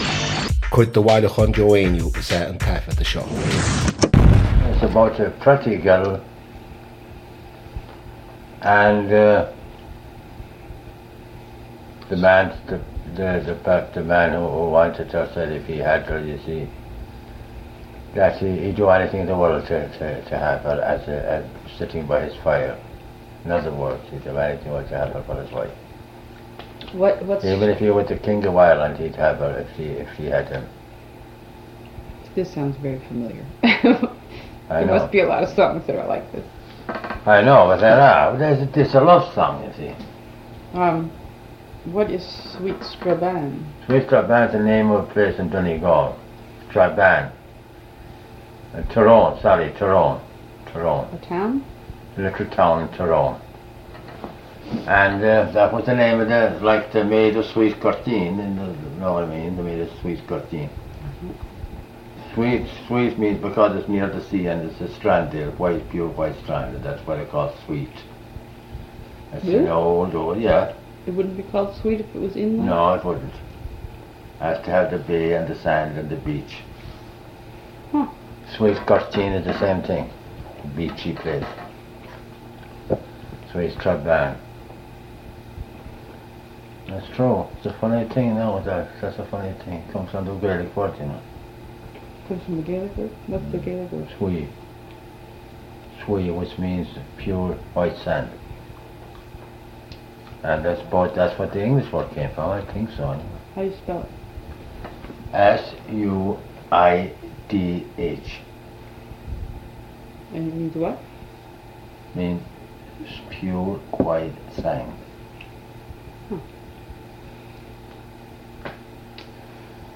• Catagóir (Category): song.
• Ainm an té a thug (Name of Informant): Joe Heaney.
• Suíomh an taifeadta (Recording Location): Ray Ridge, Brooklyn, New York, United States of America.
• Ocáid an taifeadta (Recording Occasion): private.